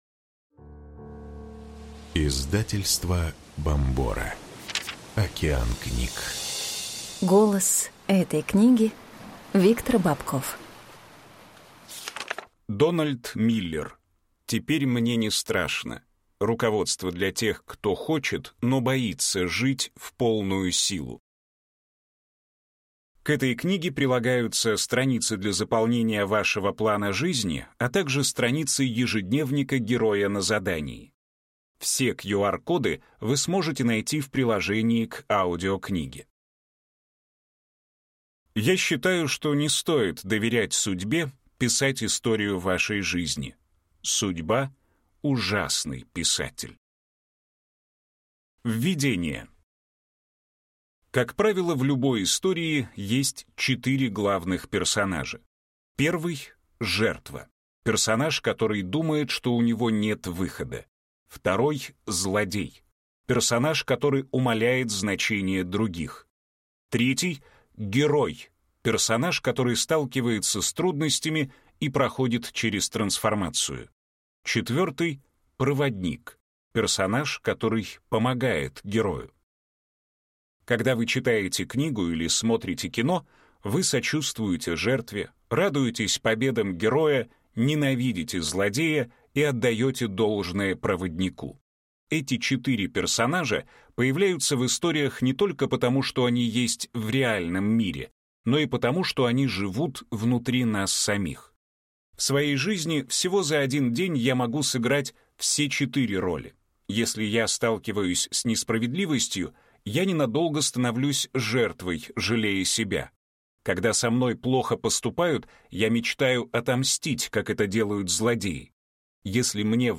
Аудиокнига Теперь мне не страшно. Руководство для тех, кто хочет, но боится жить в полную силу | Библиотека аудиокниг